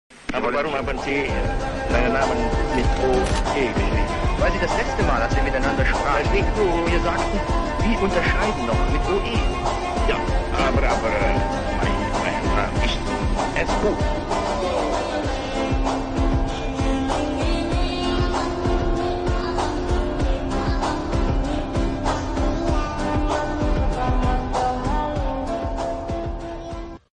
Bung Karno menyebut namanya dengan ejaan Sukarno. Hal itu dia ungkapkan saat diwawancarai wartawan Jerman pada September 1965.